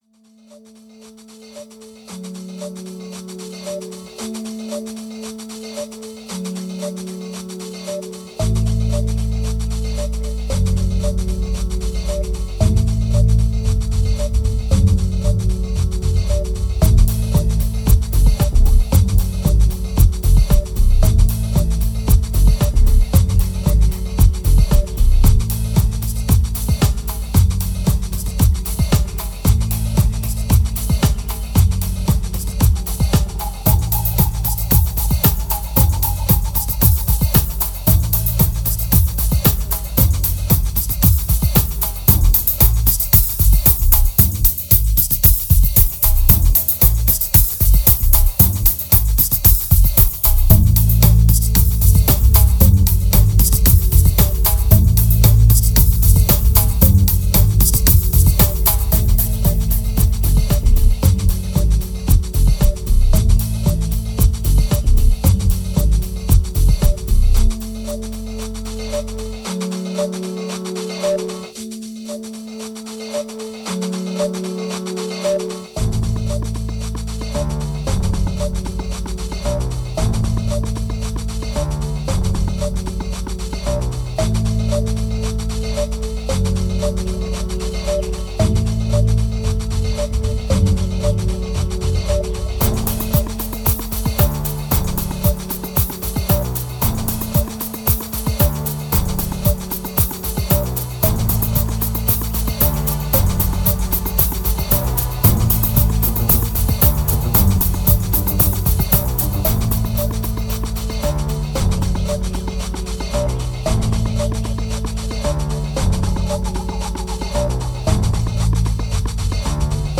2628📈 - 39%🤔 - 114BPM🔊 - 2010-03-02📅 - -13🌟